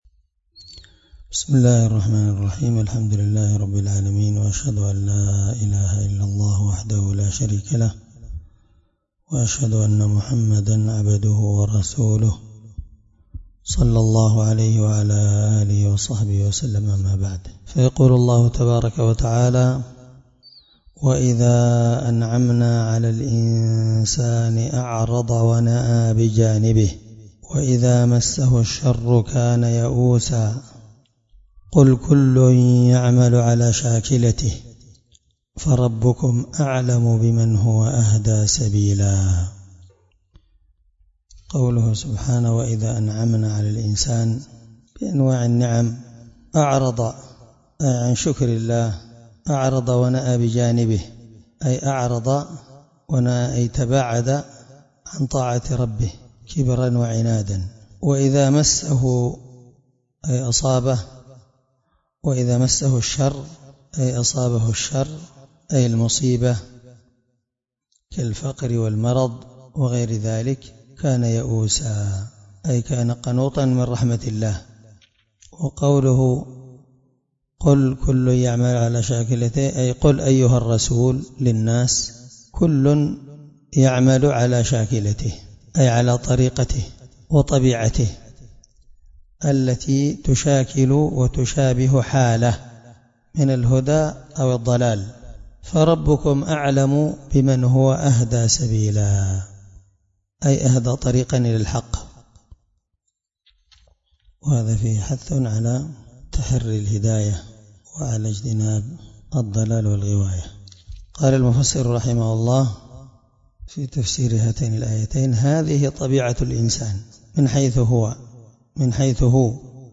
الدرس26 تفسير آية (83-84) من سورة الإسراء
17سورة الإسراء مع قراءة لتفسير السعدي